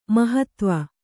♪ mahatva